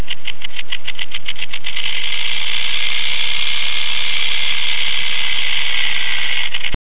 To hear the rattle - click here!
rattlersound.wav